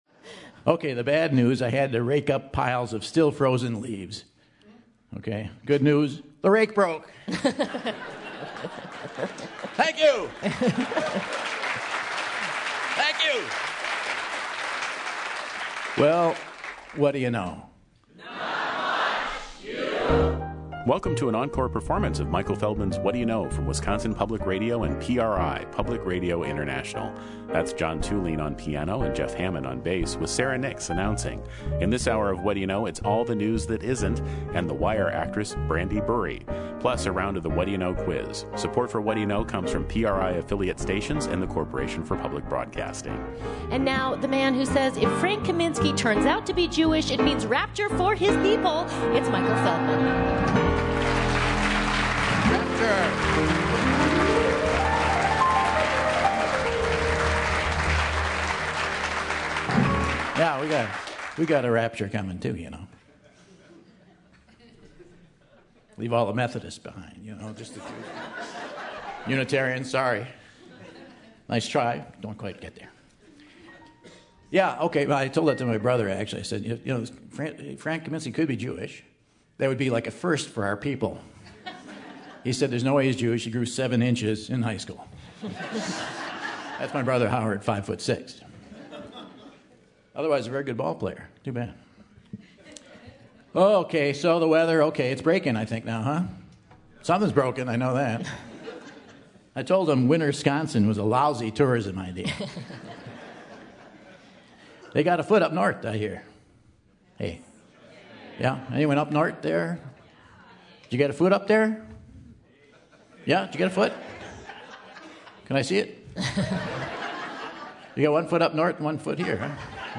August 1, 2015 - Madison, WI - Monona Terrace - Rebroadcast | Whad'ya Know?